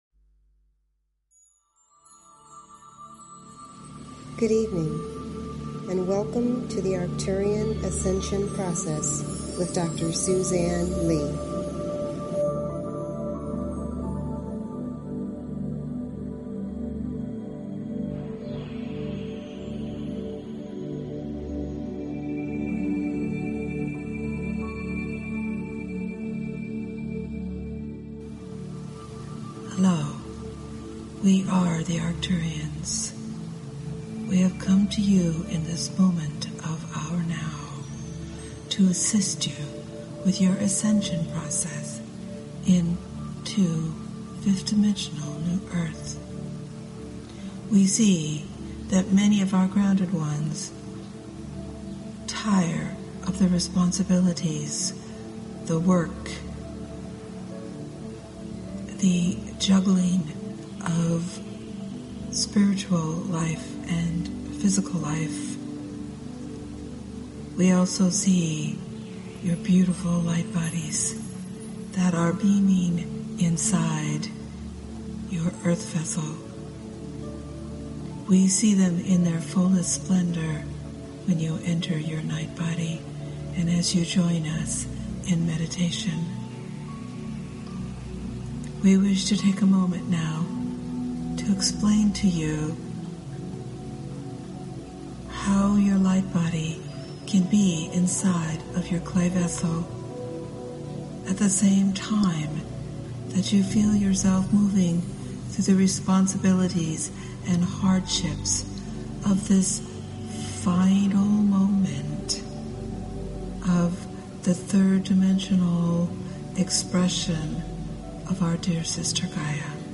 Through channeling and guided meditation